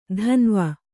♪ dhanva